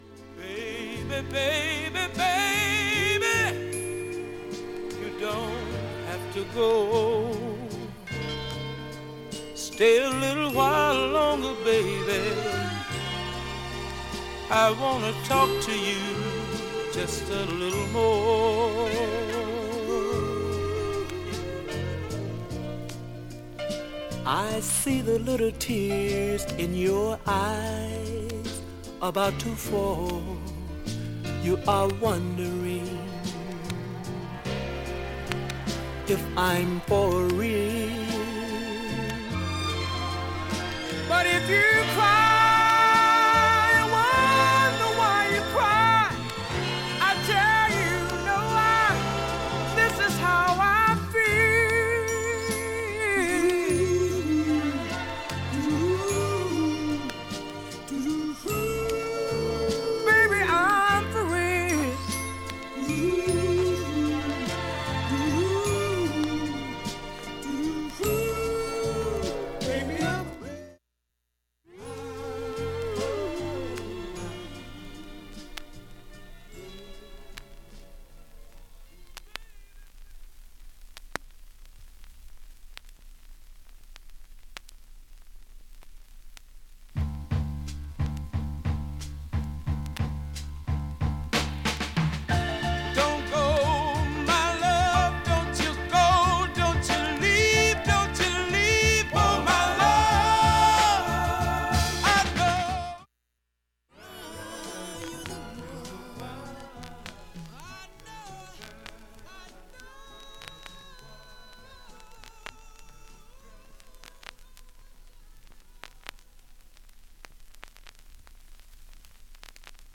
無音部でバックチリ出ていますが
3,(1m44s〜)B-5序盤に軽いチリ出ます、
演奏が大きいと聴き取れないレベル。